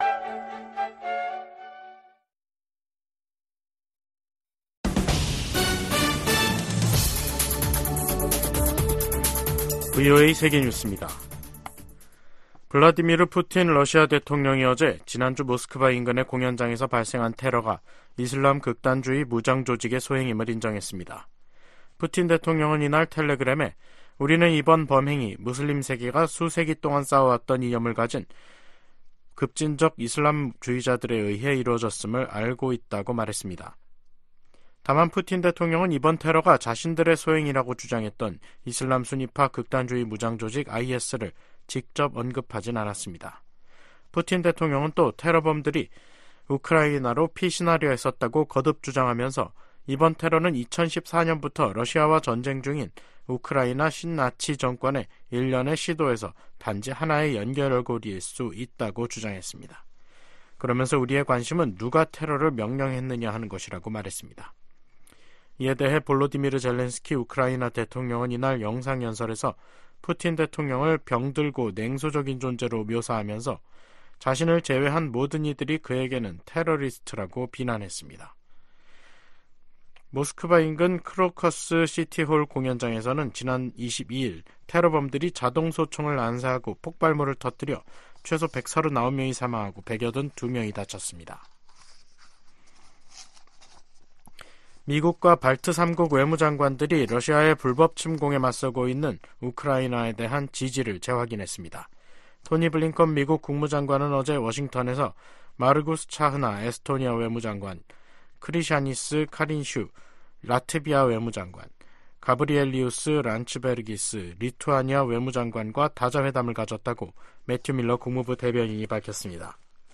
VOA 한국어 간판 뉴스 프로그램 '뉴스 투데이', 2024년 3월 26일 2부 방송입니다. 조 바이든 미국 대통령이 서명한 2024회계연도 예산안에 북한 관련 지출은 인권 증진, 대북 방송, 북한 내 미군 유해 관련 활동이 포함됐습니다. 미 국방부가 일본 자위대의 통합작전사령부 창설 계획에 대한 지지를 표명했습니다. 남북한이 다음달 군사정찰위성 2호기를 쏠 예정으로, 우주경쟁에 돌입하는 양상입니다.